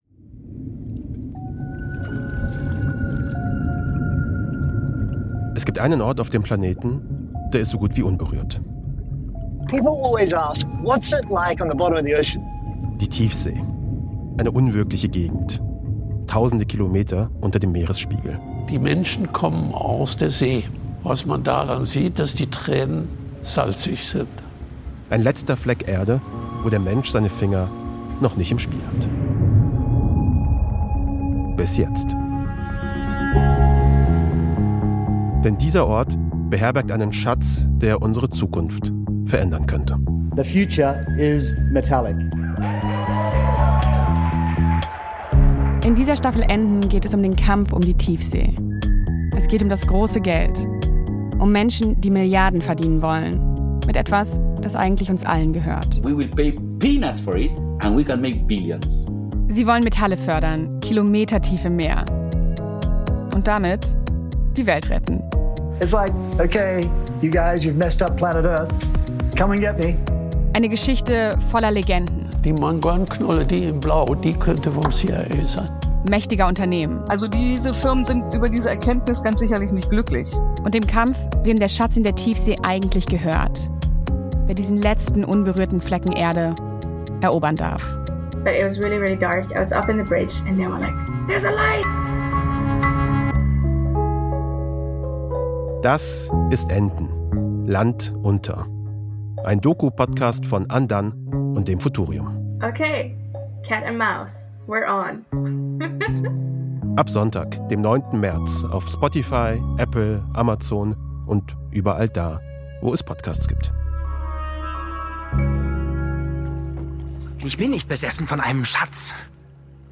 Trailer: Land Unter